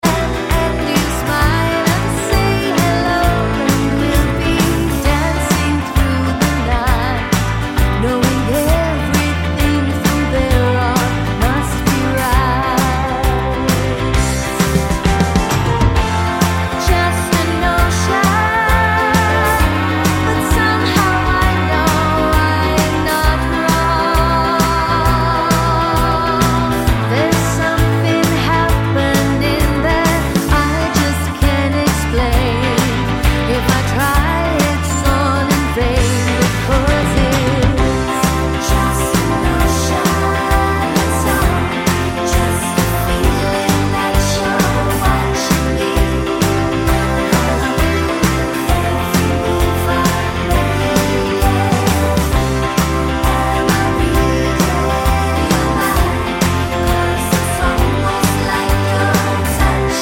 With Two Part Harmony Pop (1970s) 3:26 Buy £1.50